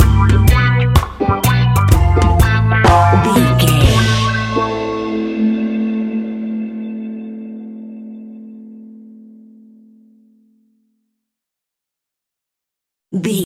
Classic reggae music with that skank bounce reggae feeling.
Aeolian/Minor
laid back
off beat
skank guitar
hammond organ
percussion
horns